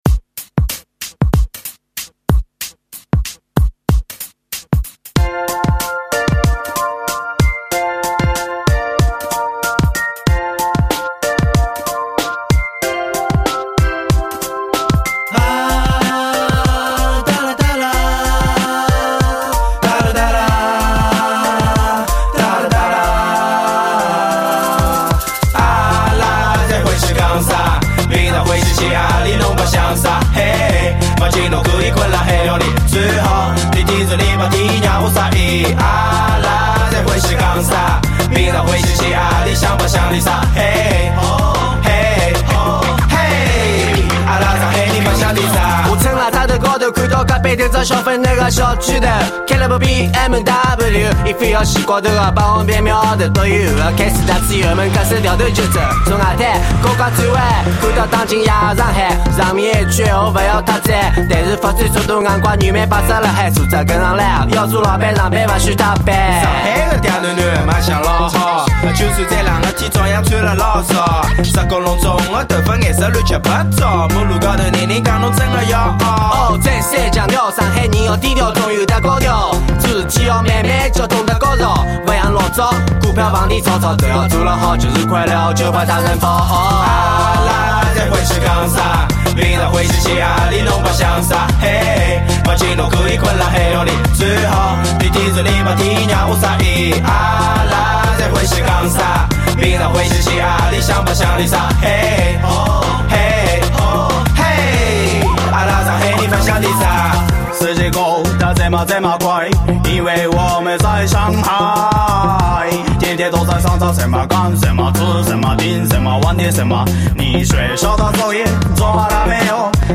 上海话Hiphop/Rap
在上海，有这么一群人，他们用上海话唱着Hiphop/Rap。